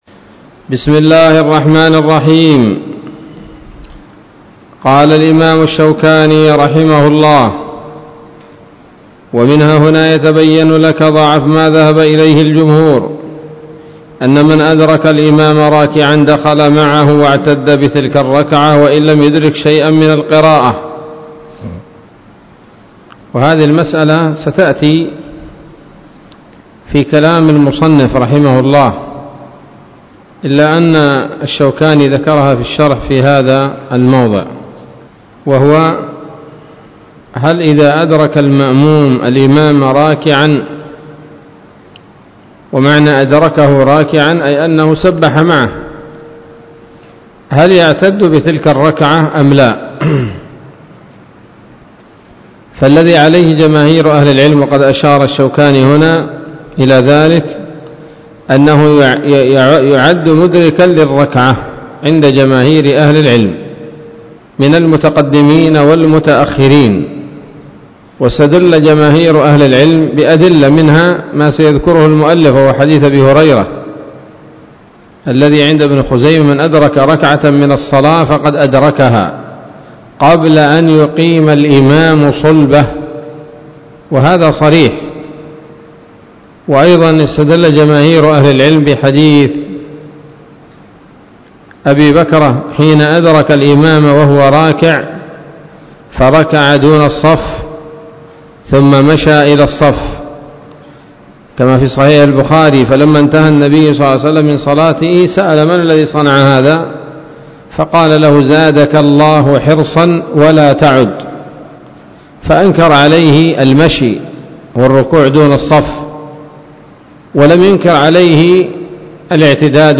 الدرس الخامس والثلاثون من أبواب صفة الصلاة من نيل الأوطار